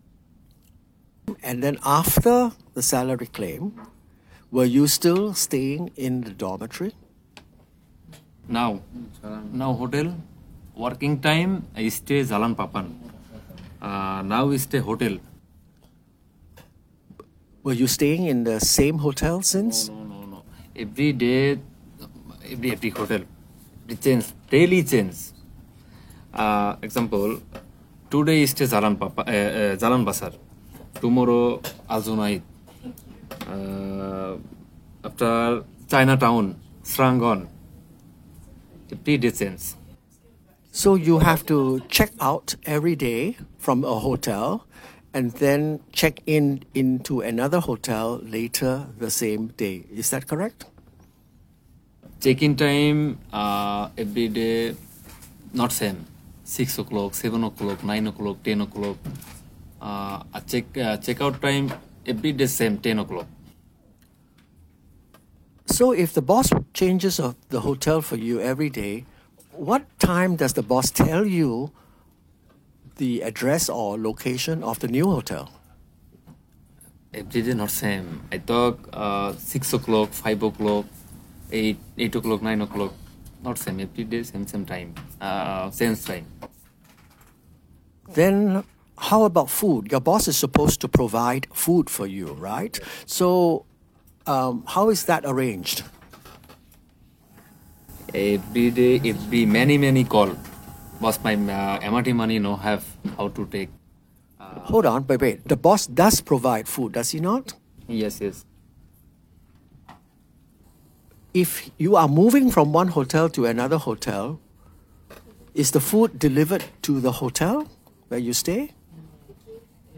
When seven workers came to TWC2’s office one day to update us about their salary case progress, we asked about their accommodation and learned that three had been evicted.
As can be heard, the men say that while the employer “provides” meals, they are provided at a location that is unconnected with their accommodation.